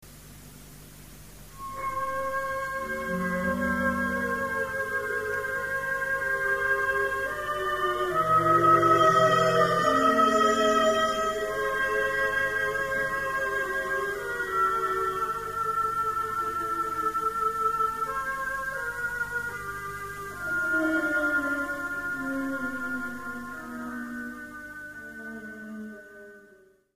Preludio para Orquesta n° 2, "Soledad del hombre", 1930